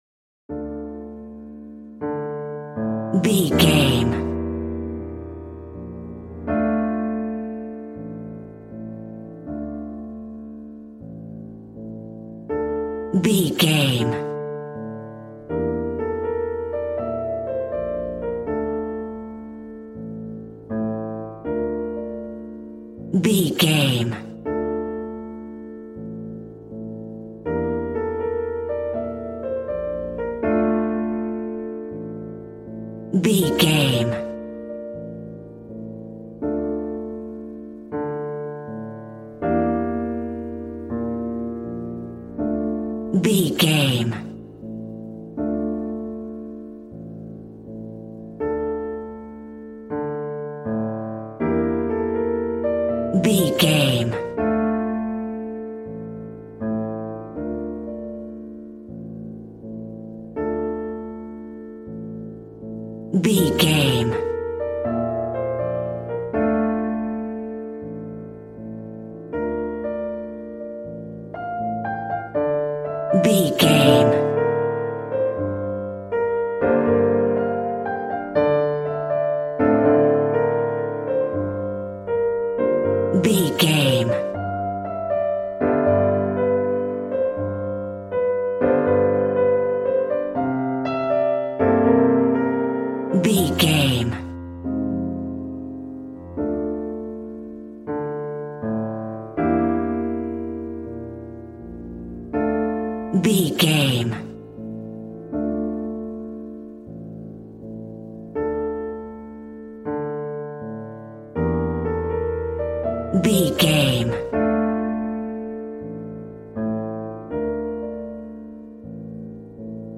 Smooth jazz piano mixed with jazz bass and cool jazz drums.,
Aeolian/Minor
D
piano
drums